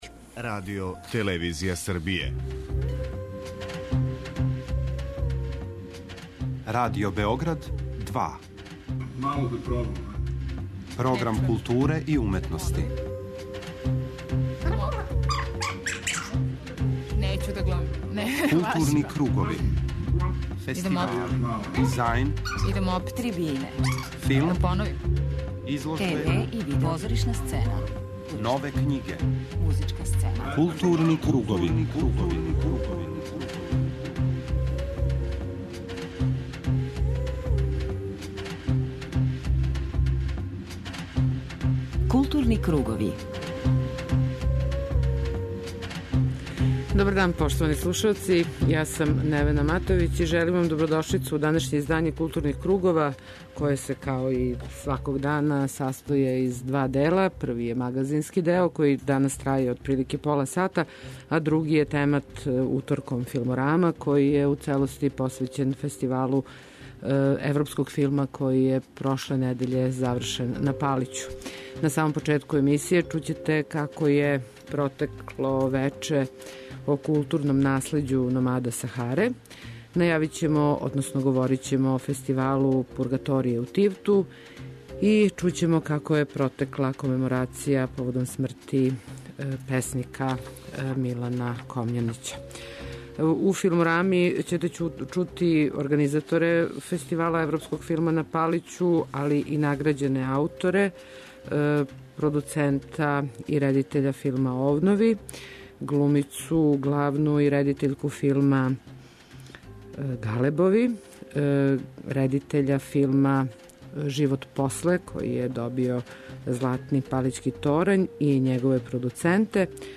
У рубрици Крупни план чућете интервју са редитељем Ројем Андерсоном, који је заједно са Желимиром Жилником овогодишњи добитник награде Александар Лифка .